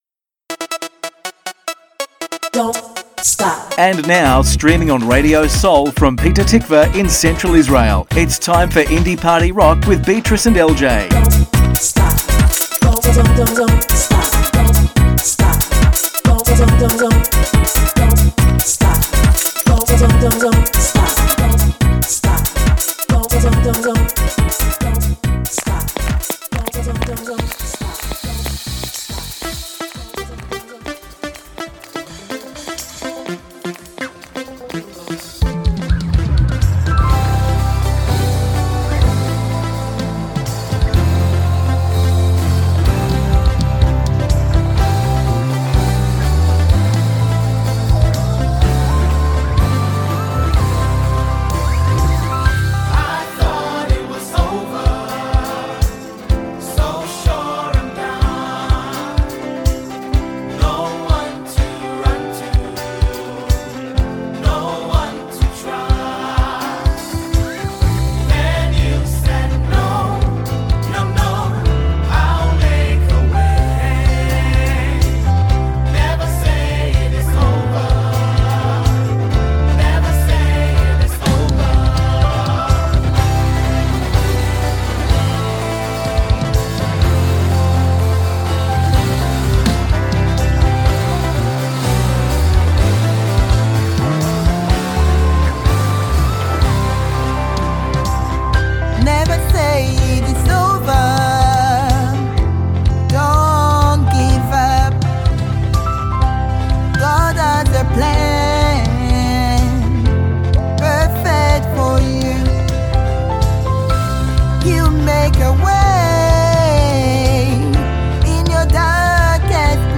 מוזיקת קאנטרי ואינדי עולמית - התכנית המלאה 24.1.25